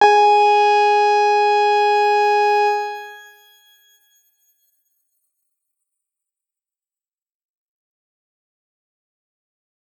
X_Grain-G#4-pp.wav